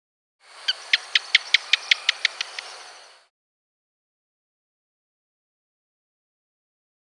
Звук пищащего геккона записанный в Таиланде